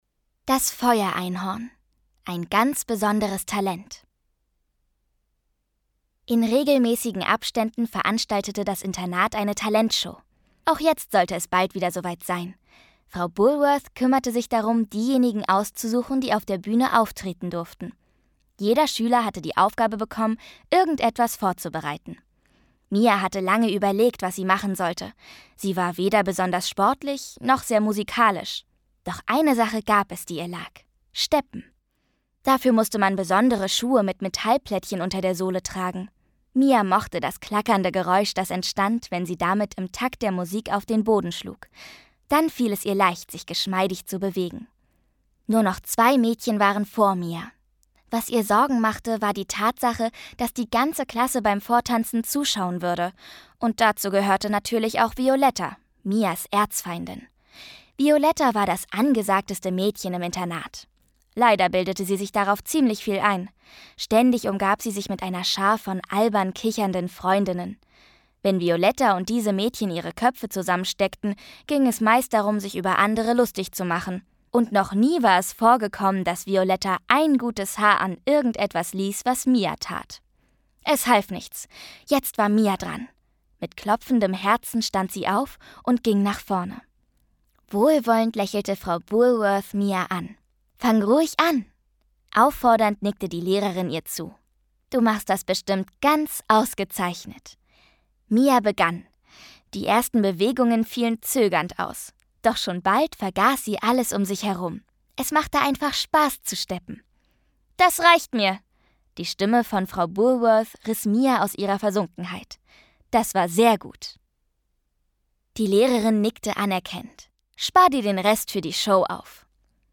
Lesung mit Musik